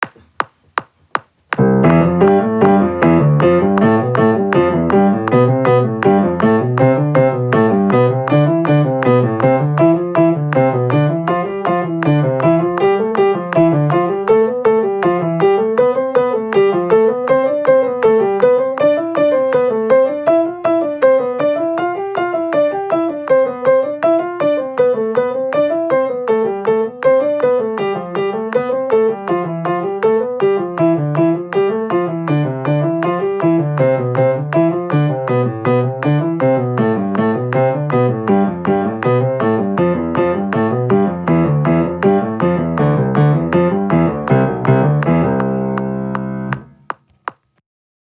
♩ =160 （クリック音）